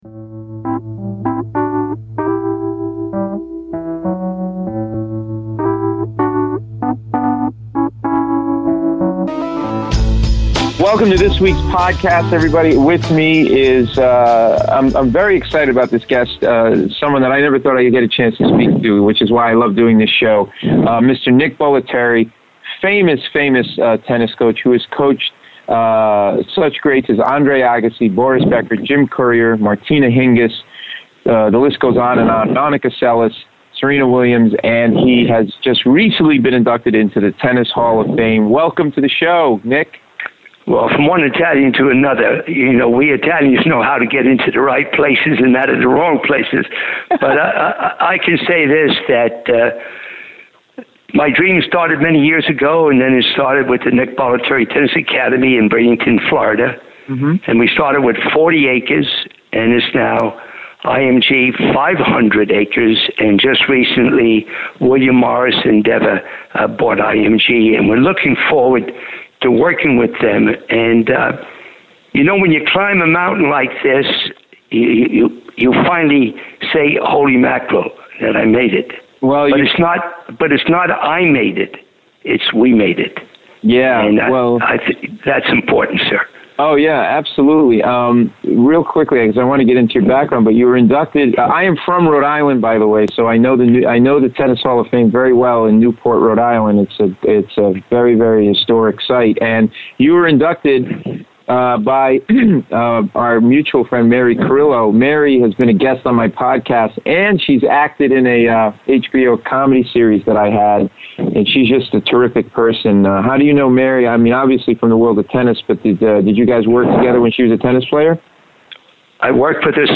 With it being the week of the U.S. Tennis Open I thought I'd bring you and interview with the controversial, Nick Bollettieri - THE premier tennis coach and mentor in the sport.